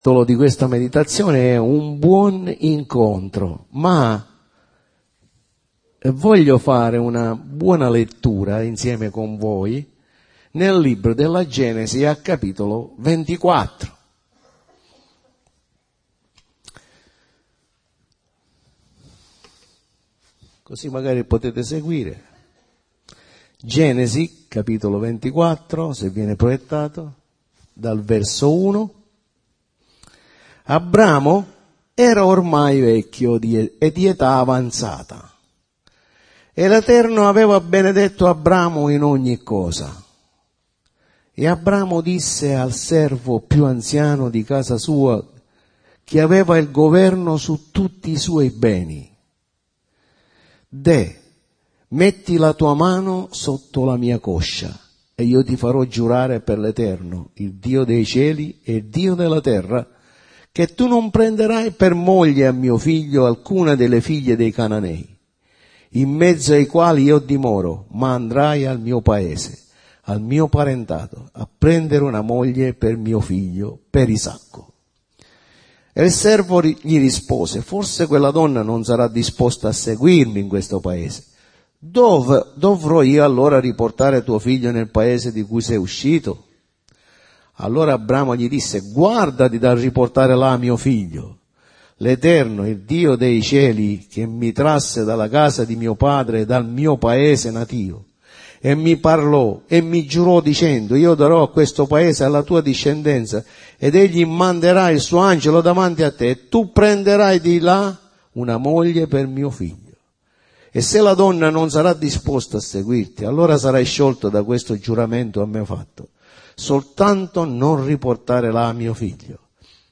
Sezione del sito per l'ascolto dei messaggi predicati la domenica e per il riascolto di studi biblici
Clicca per ascoltare o scaricare la pedicazione " Un buon incontro"